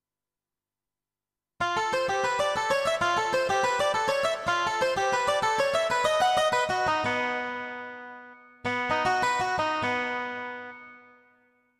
13. I SUONI - GLI STRUMENTI XG - GRUPPO "GUITAR"
10. Mandolin
XG-03-10-Mandolin.mp3